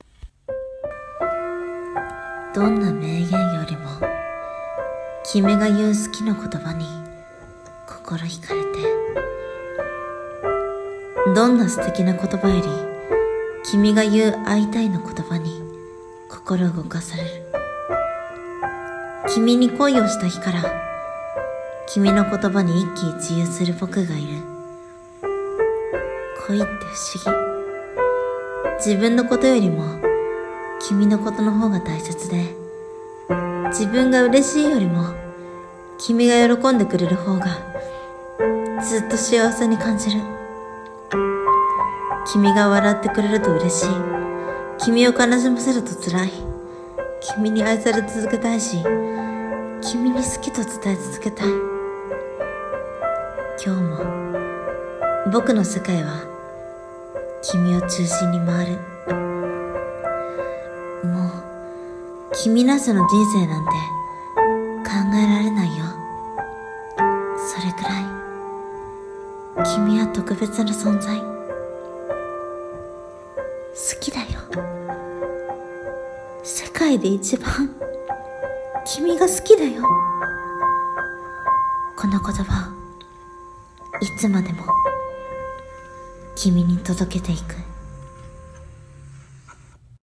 声劇【恋愛】※恋愛声劇